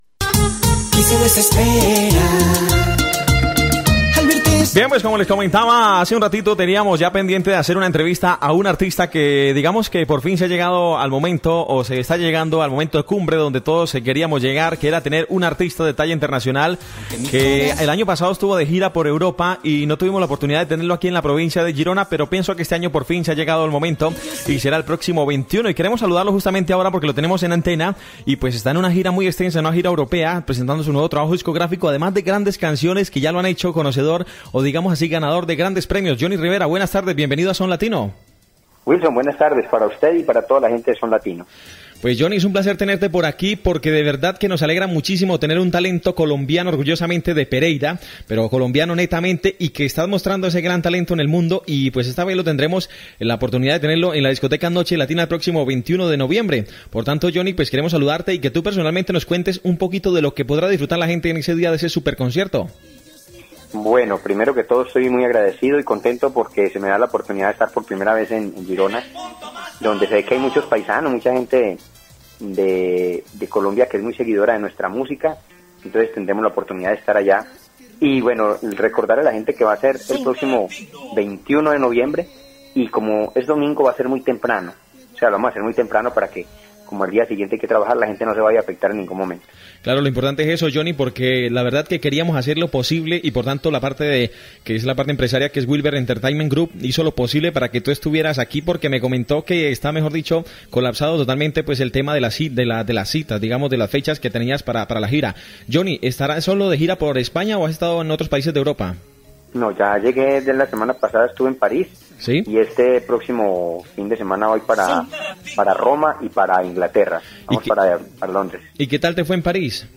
Entrevista telefònica al cantant colombià Jhonny Rivera abans que actui a Empúria Brava
Entreteniment